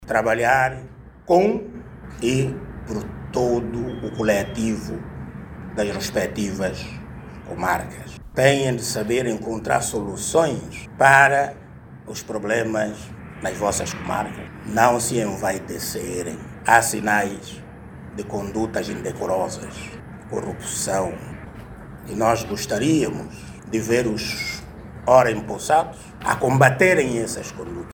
O Juiz Presidente do Conselho Superior da Magistratura Judicial apela aos 23 juízes presidentes dos tribunais de Comarca de 15 províncias judiciais a combater à corrupção no sector da justiça e a má conduta de alguns juízes. Norberto Sodré fez este pronunciamento durante o acto de tomada de posse dos novos magistrados.